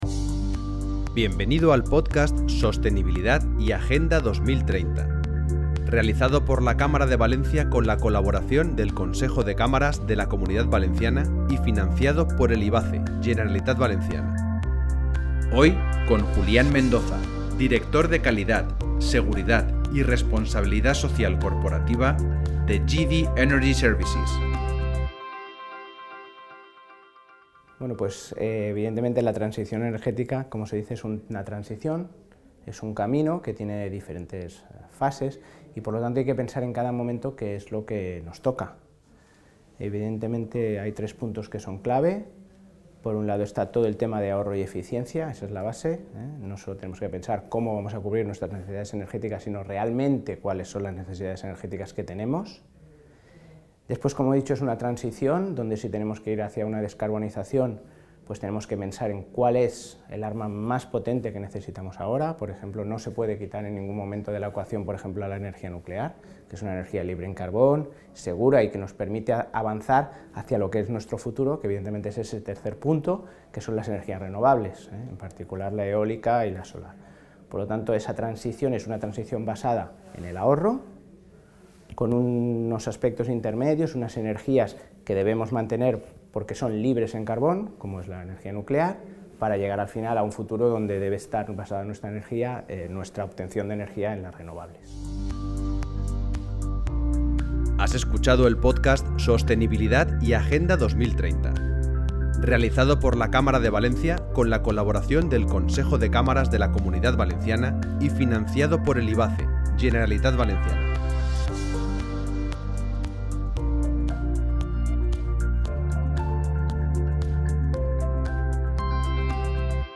Con el podcast “La revolución 4.0” pretendemos realizar una foto desde dentro de las propias compañías, entrevistando a los CEO, directivos y/o responsables de la transformación digital de un gran número de empresas de Valencia, algunas de la Comunidad Valencia y también del territorio nacional.